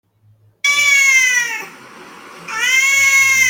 Miau, Miau
GATO (audio/mpeg)